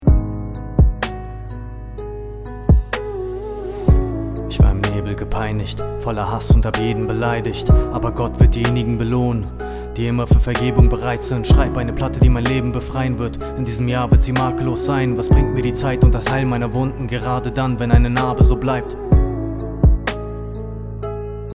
ich habe ohne Effekte aufgenommen und dann bei der letzten aufnähme bisschen Compressor und Hall dazu gegeben.
und ihr hört es wahrscheinlich schon, klingt nicht schön, leicht verzerrt obwohl ich nur einen Compressor dazu geschaltet haben.
ohne Low Cut mit Compressor und Hall.mp3